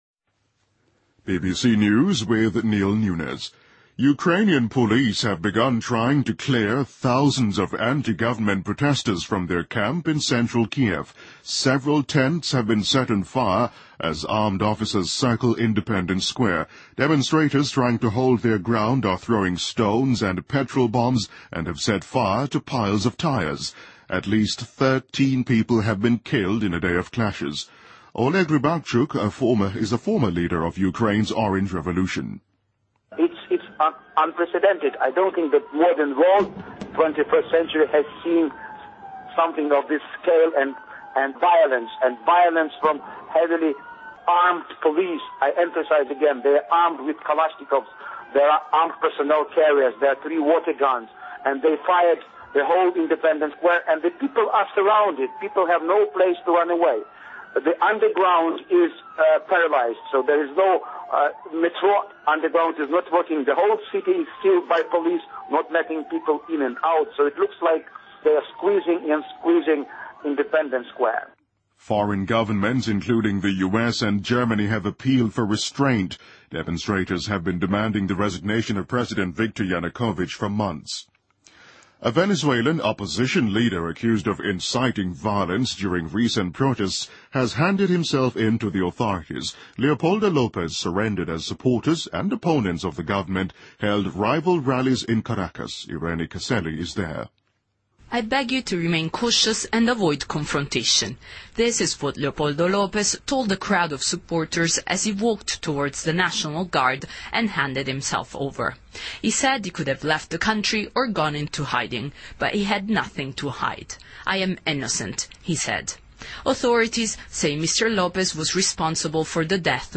BBC news,2014-02-19